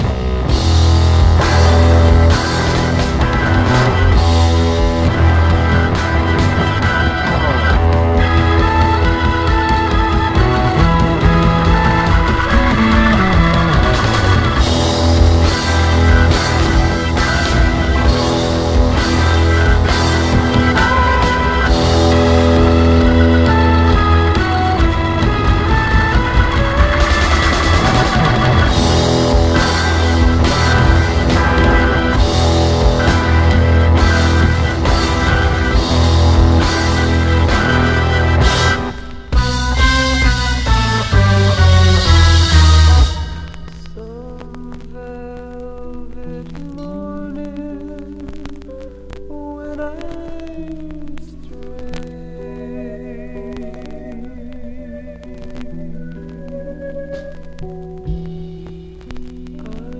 ROCK / 60'S / PSYCHEDELIC